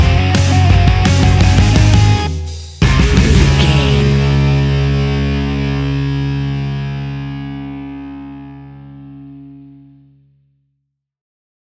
Uplifting
Ionian/Major
D♭
hard rock
distortion
punk metal
rock instrumentals
rock guitars
Rock Bass
heavy drums
distorted guitars
hammond organ